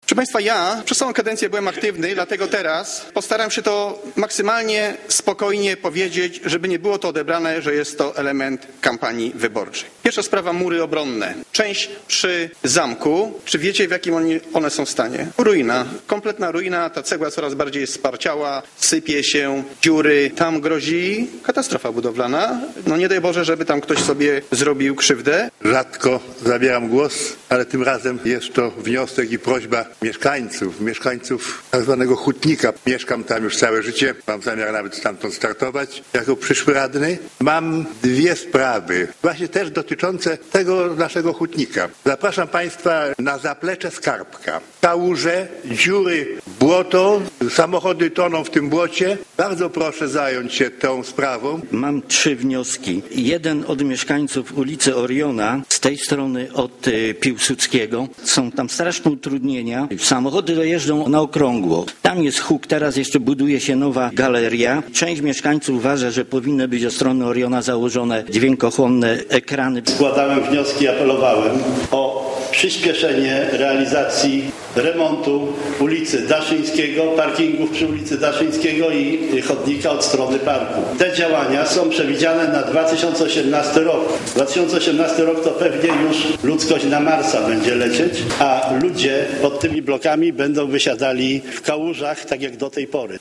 Podczas ubiegłotygodniowego posiedzenia kilku rajców złożyło wnioski w imieniu swoich wyborców. Byli wśród nich przedstawiciele wszystkich opcji politycznych.
Głos zabrali między innymi Sławomir Majewski, Eugeniusz Patyk, Antoni Krechowiec i Roman Bochanysz.